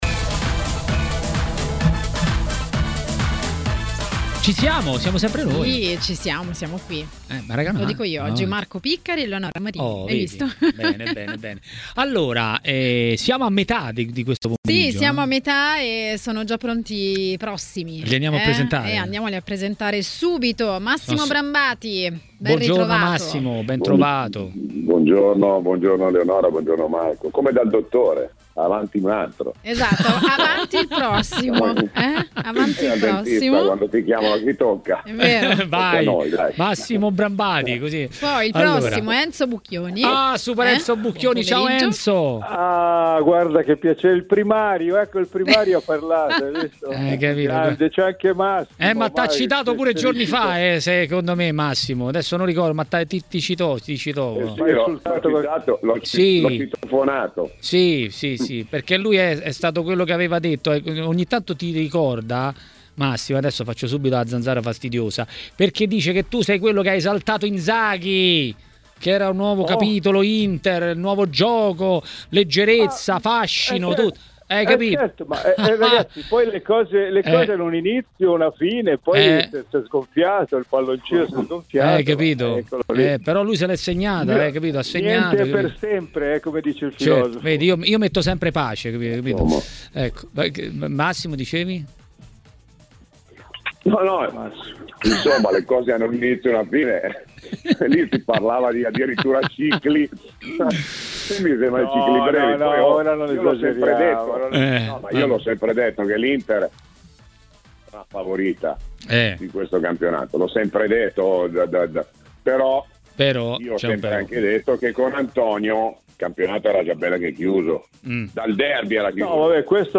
A Maracanà, trasmissione di TMW Radio